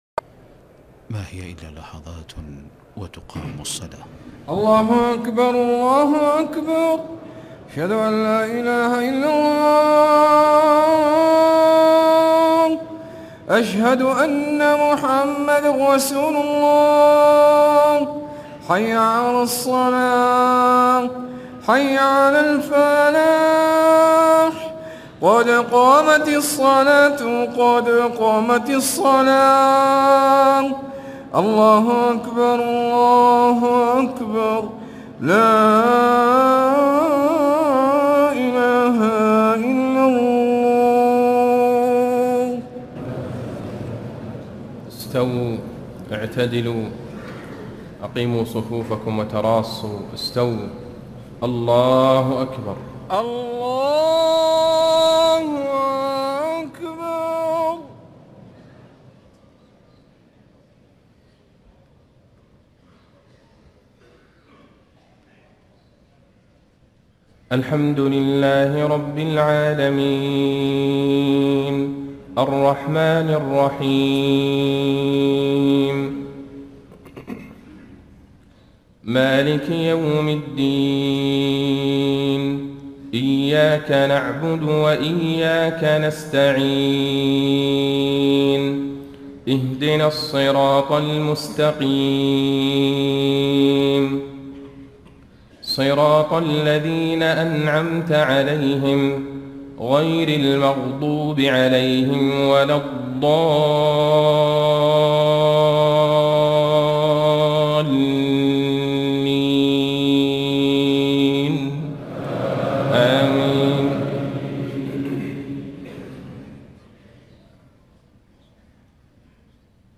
صلاة المغرب 9 - 7 - 1435هـ سورتي التكاثر و العصر > 1435 🕌 > الفروض - تلاوات الحرمين